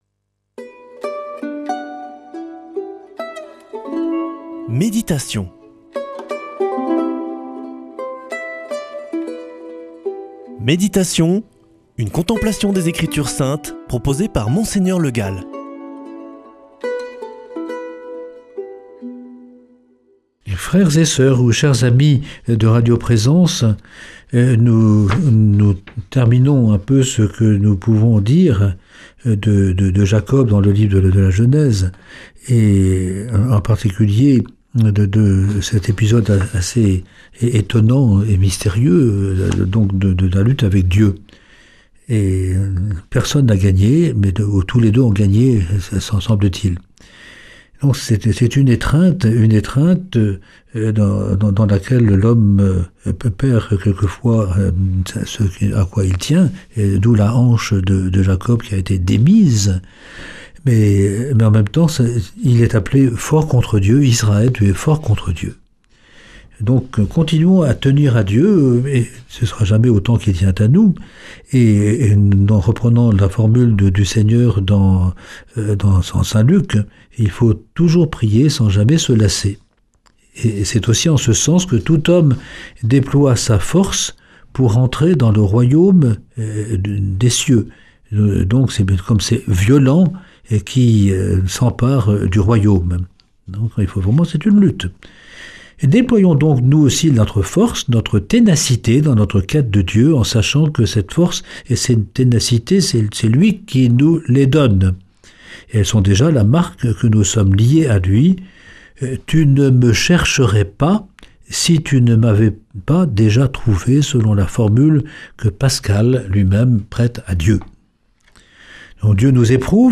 Méditation avec Mgr Le Gall